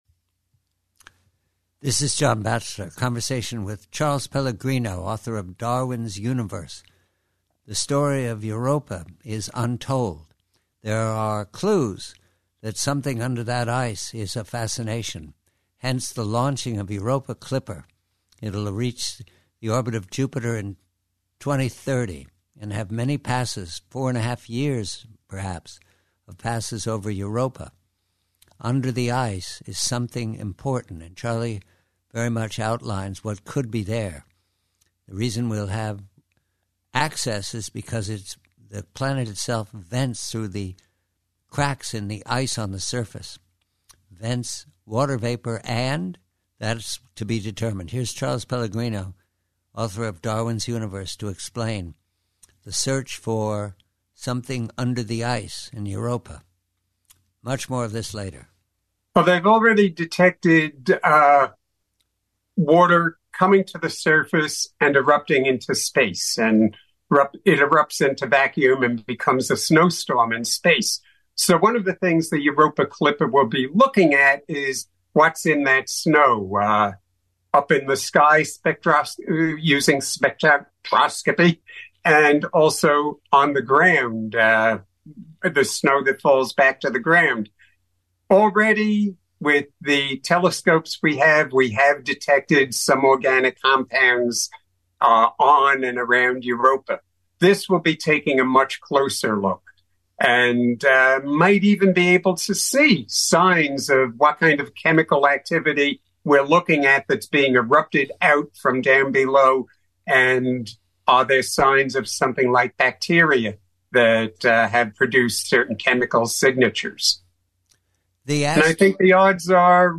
Preview: Europa: Conversation with colleague Charles Pellegrino, author of "Darwin's Universe," regarding the ambition of the Europa Clipper probe to detect what's under the ice.